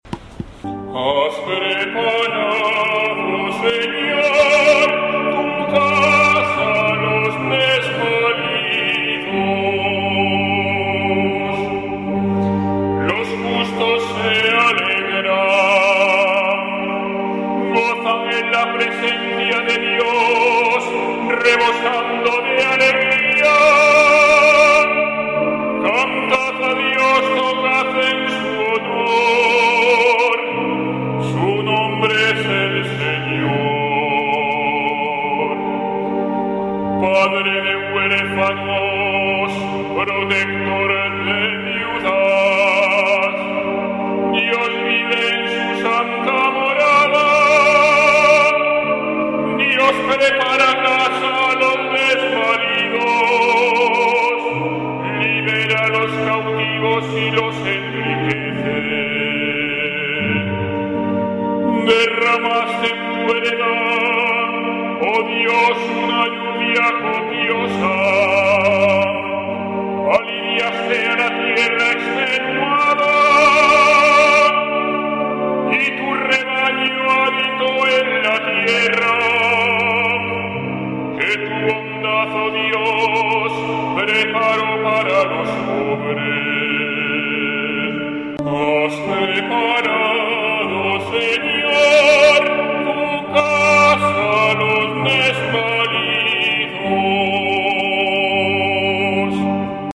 Salmo Responsorial 67/ 4-7; 10-11